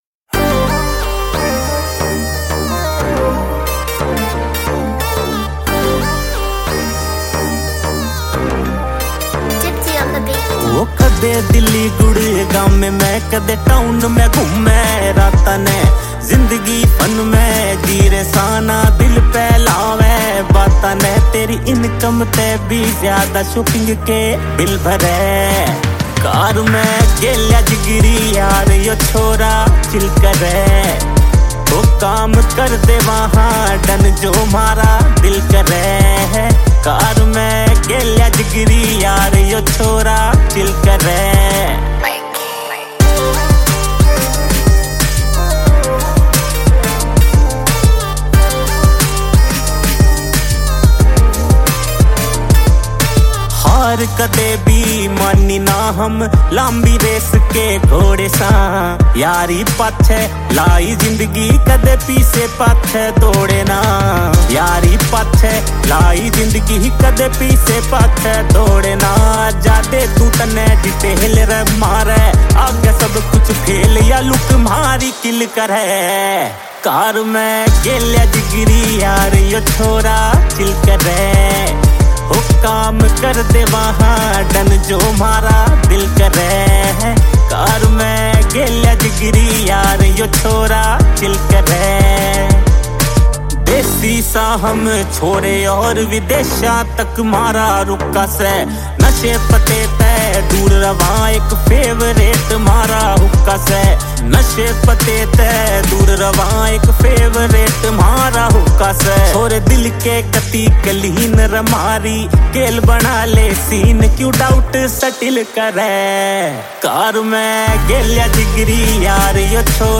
Category: Haryanvi